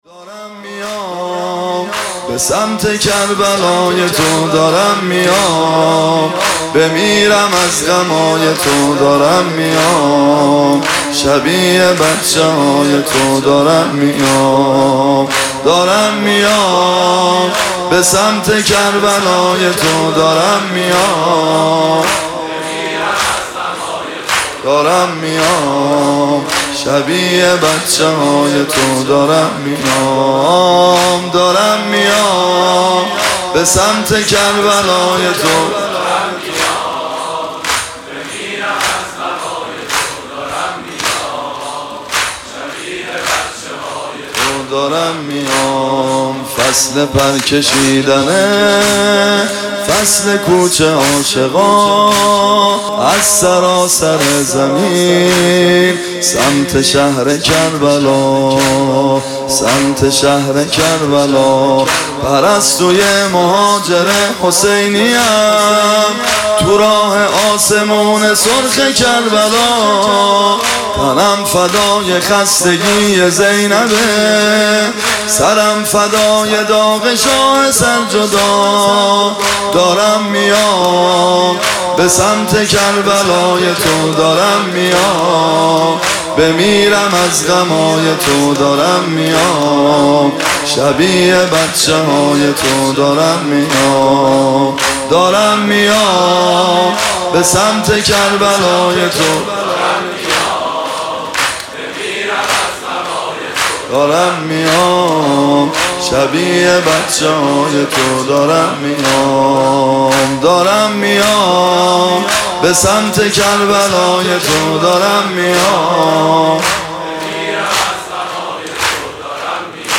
ویژه پیاده روی اربعین/ 13
متن مداحی